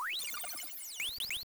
giggle.wav